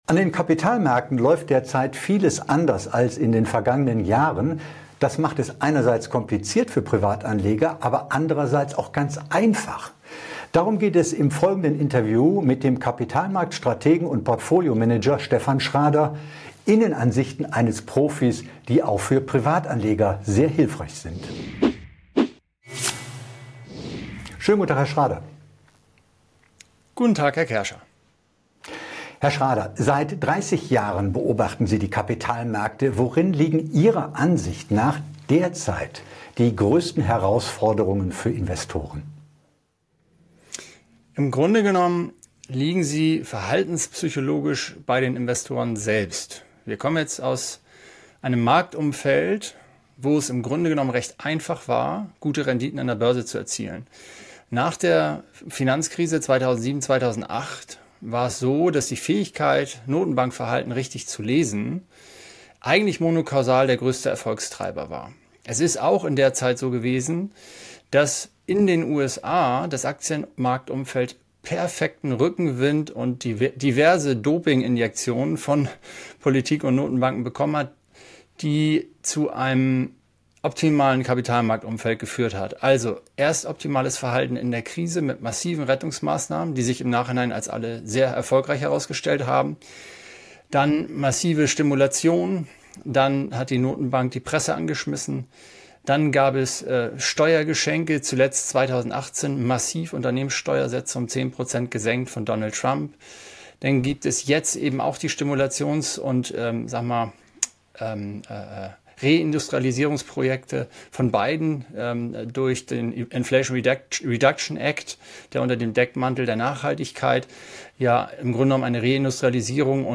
In diesem exklusiven Interview